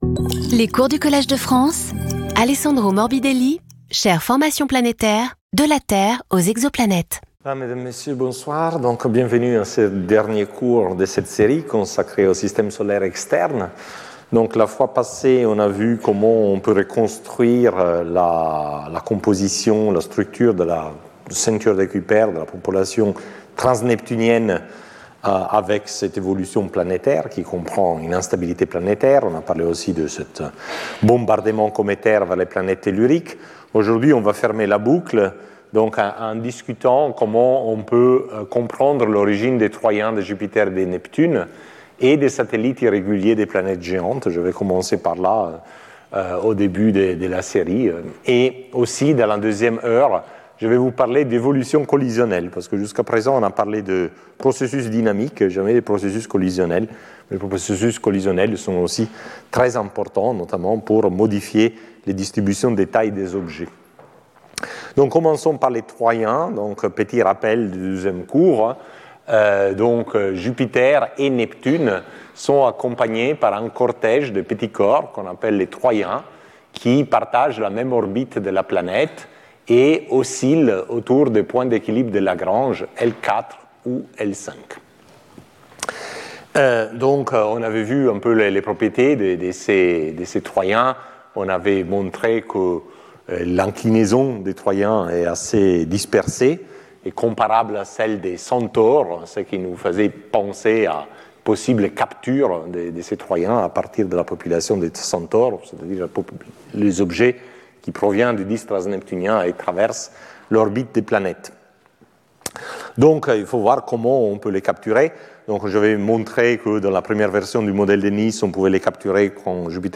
Speaker(s) Alessandro Morbidelli Professor at the Collège de France